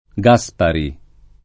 vai all'elenco alfabetico delle voci ingrandisci il carattere 100% rimpicciolisci il carattere stampa invia tramite posta elettronica codividi su Facebook Gaspari [ g#S pari ] cogn. — qualche famiglia, però, g a S p # ri